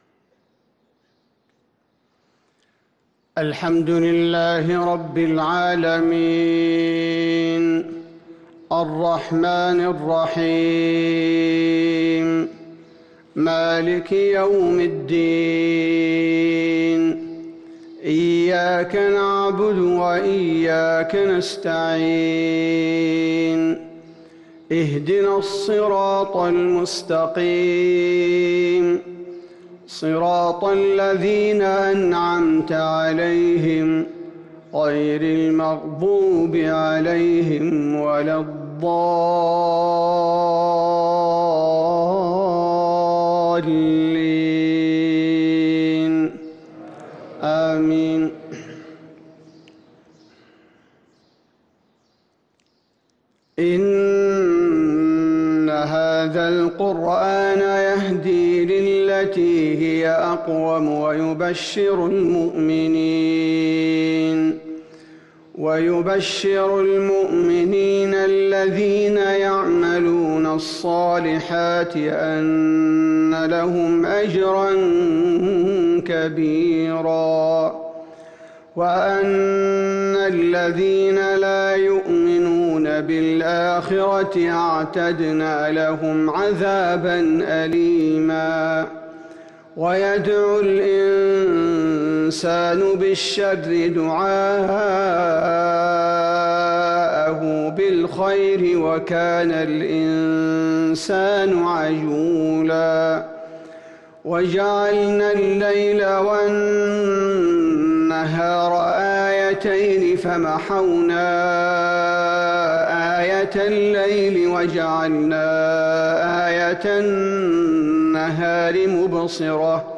صلاة الفجر للقارئ عبدالباري الثبيتي 5 صفر 1443 هـ
تِلَاوَات الْحَرَمَيْن .